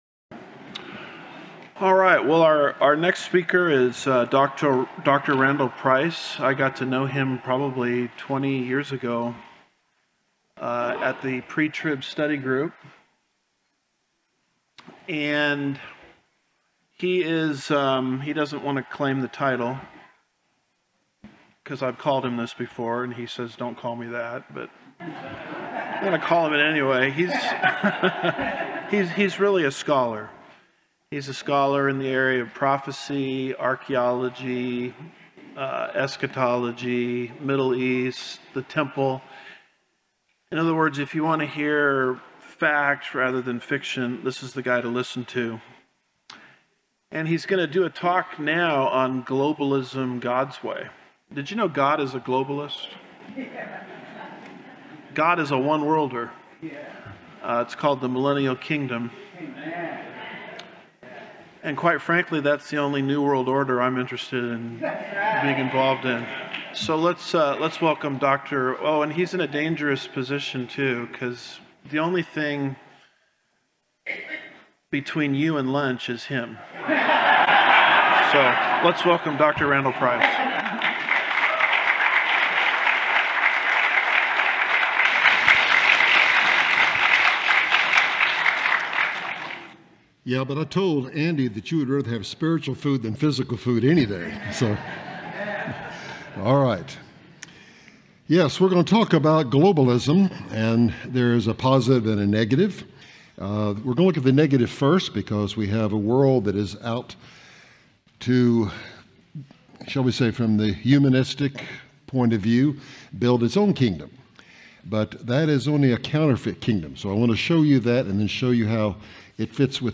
2025 Prophecy Conference